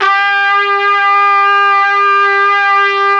RED.BRASS 25.wav